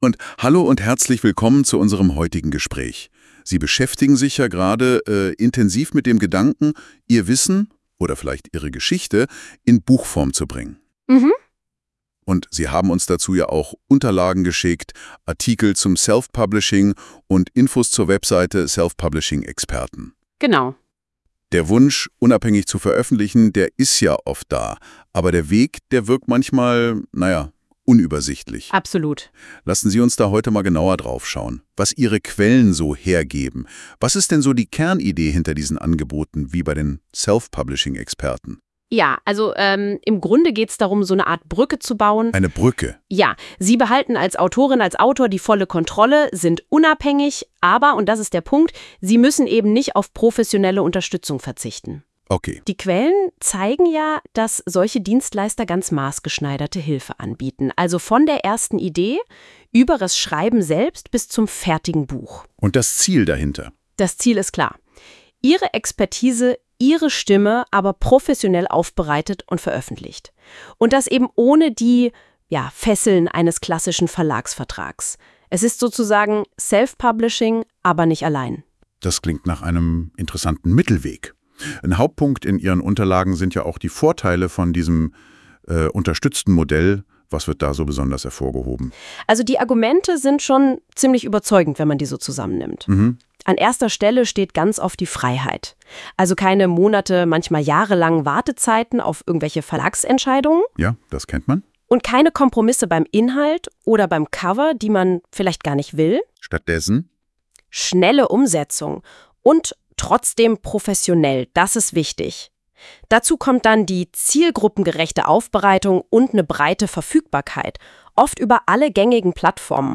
Das Prinzip ist recht simpel: Eine männliche und eine weibliche Stimme unterhalten sich. Leider sogar täuschend menschenähnlich.